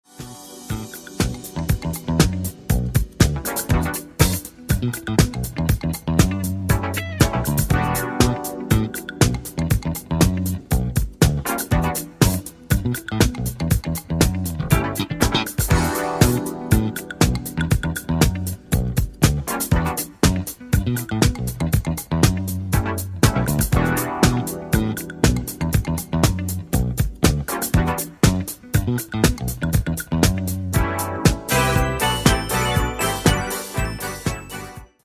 Genere:   Jazz Funk